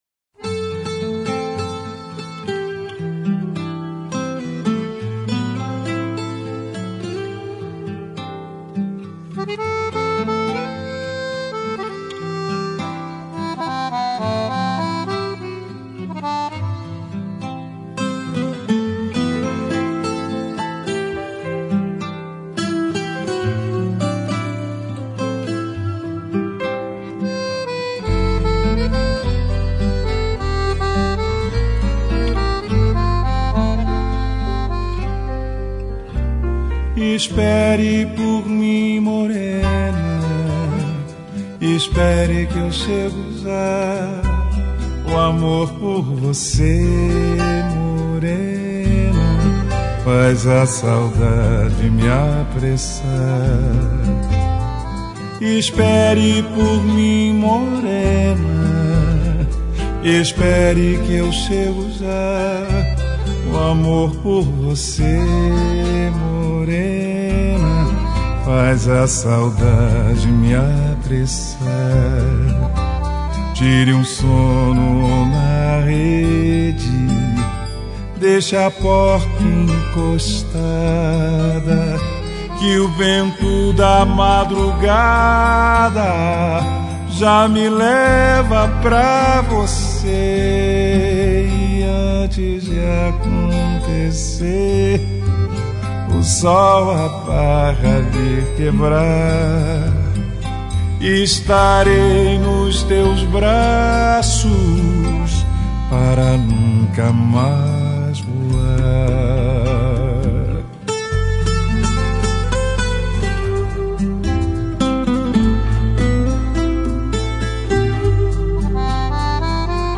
Boleros